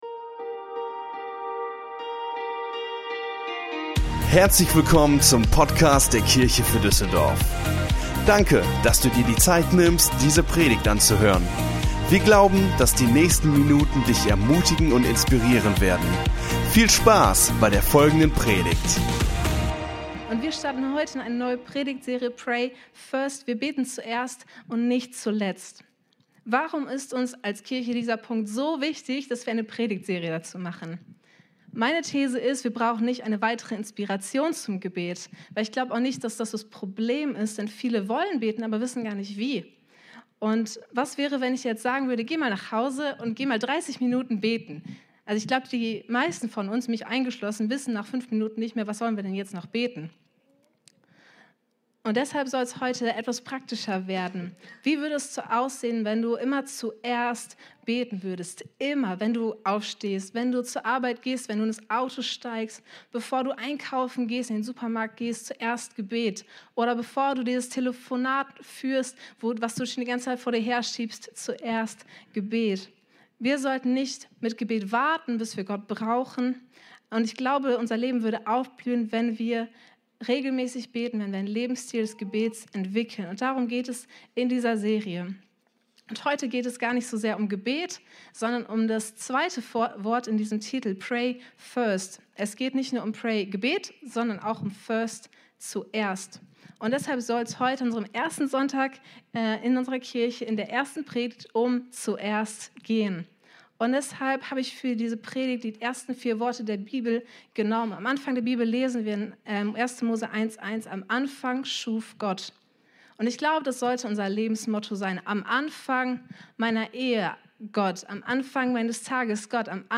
Unsere Predigt vom 15.01.23 Predigtserie: Pray First Teil 1 Folge direkt herunterladen